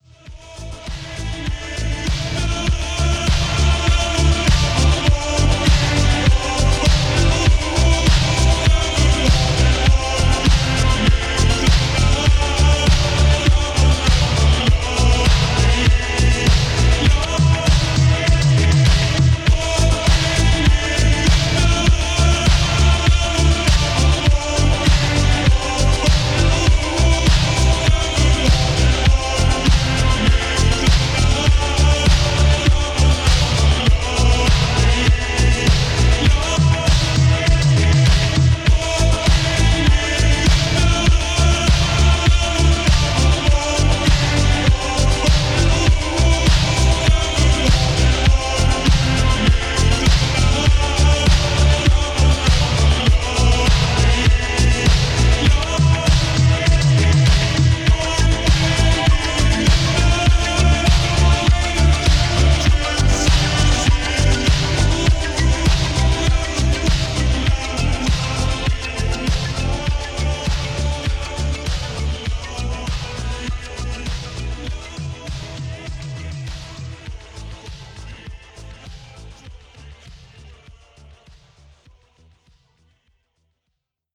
Второе- кто-то из евродиско , мелодия в голове вертится.